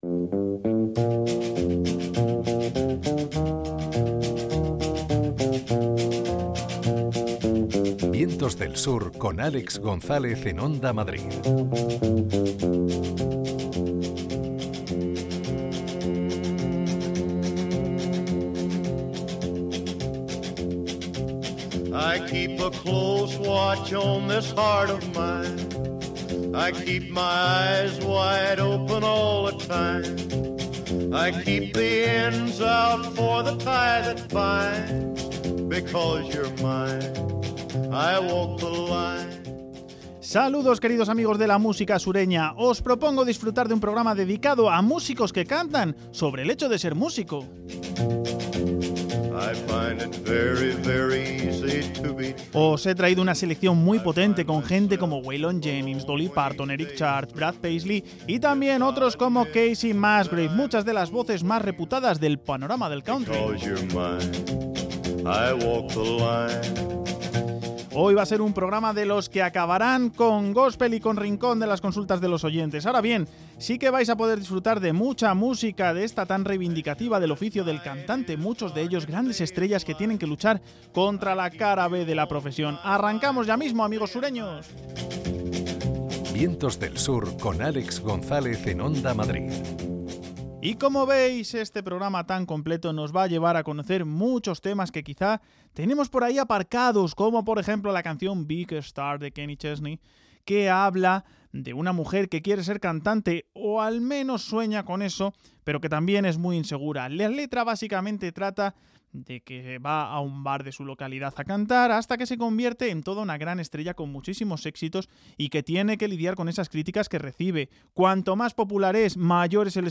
También hay espacio para las cuestiones de los oyentes y un himno de góspel final.